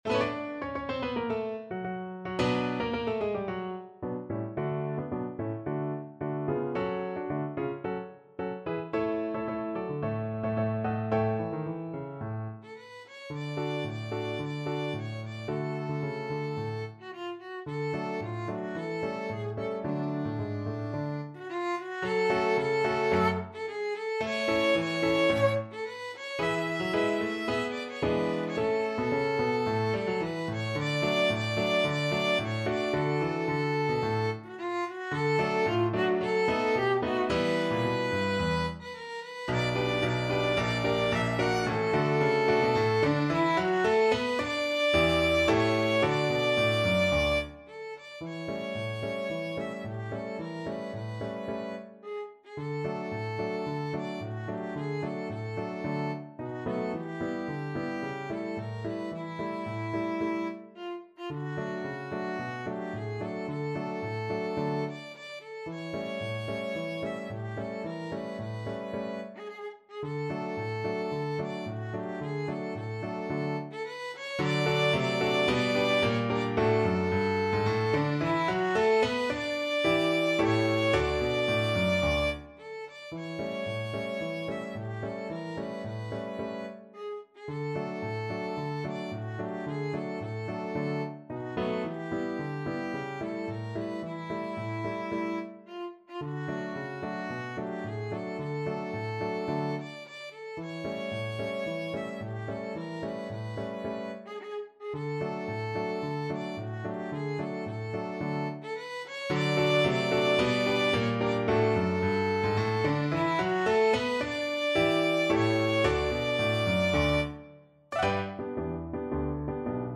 2/2 (View more 2/2 Music)
D5-F#6
Classical (View more Classical Violin Music)